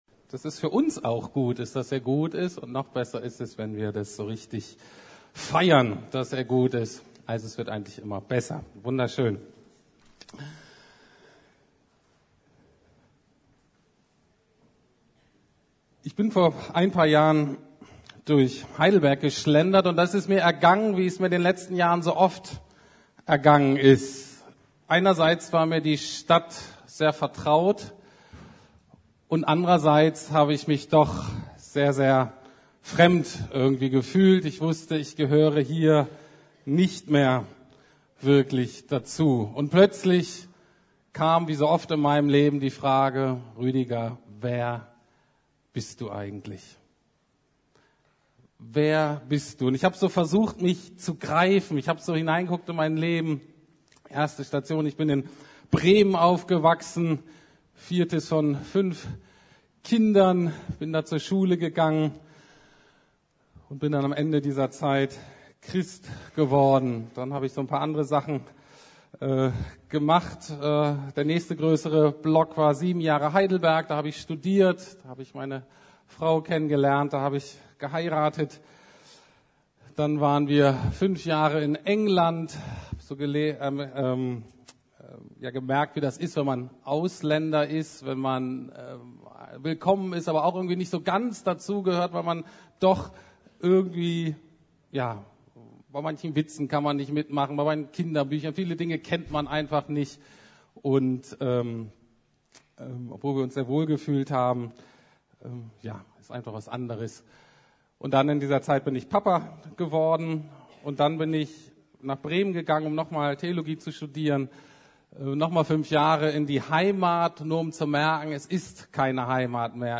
Neues Leben in Christus - Identität ~ Predigten der LUKAS GEMEINDE Podcast